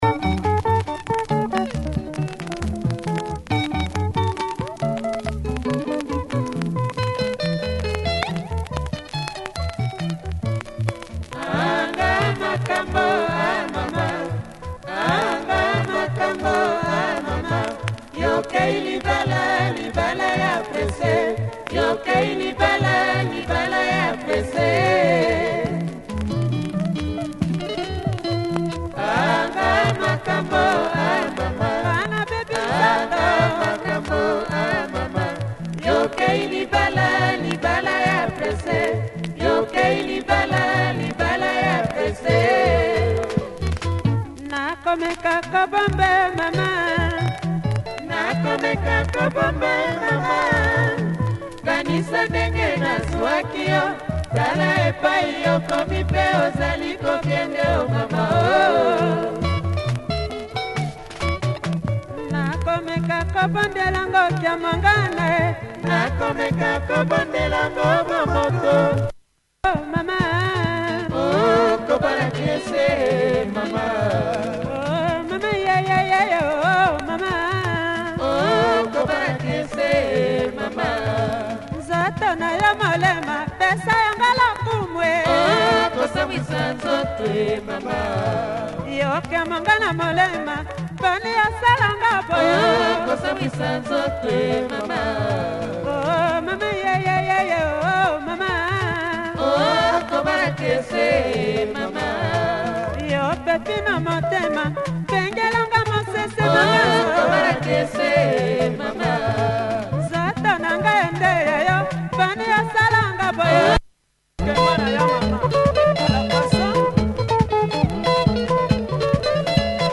Lovely guitar
has a few marks that affect play